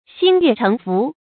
xīn yuè chéng fú
心悦诚服发音
成语正音 服，不能读作“fù”。